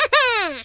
yoshi_yahoo2.ogg